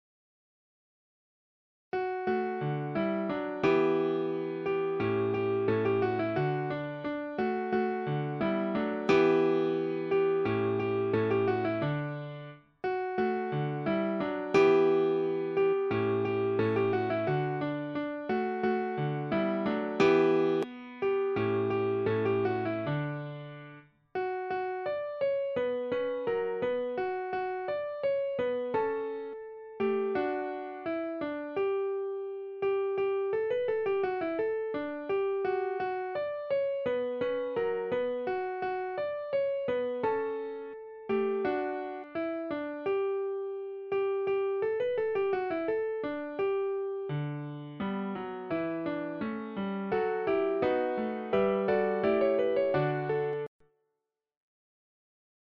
Cahier Kessler, page 28, huit lignes sur deux portées.